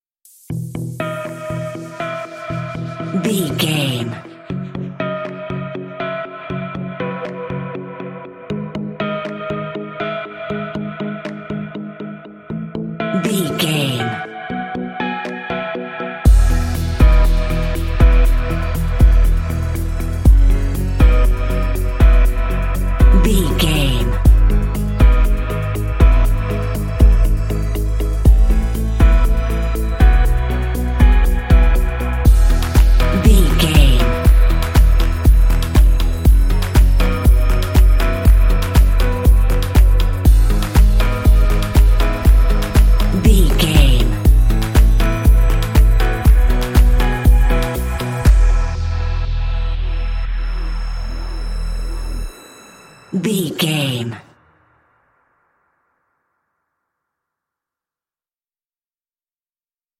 is an Uplifting, Upbeat, and good-feeling track
Ionian/Major
Fast
electronic
piano
synth
pop
energetic
drum machine